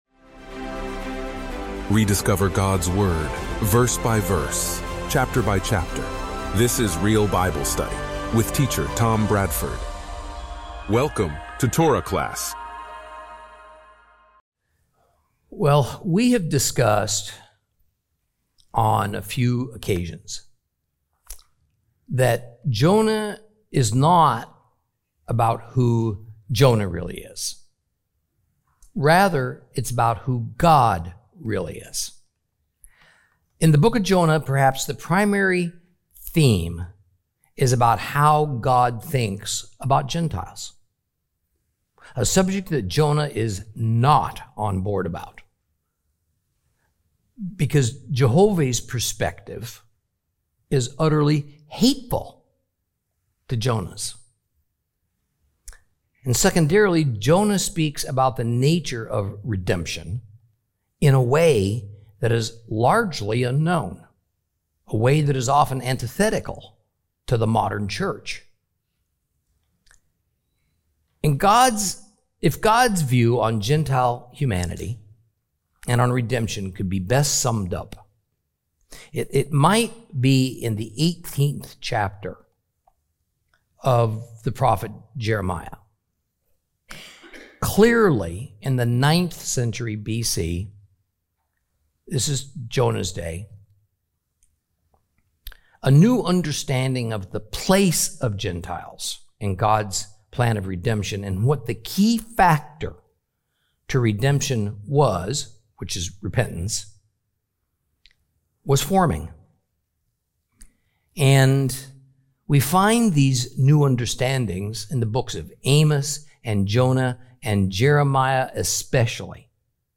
Teaching from the book of Jonah, Lesson 6 Chapter 3.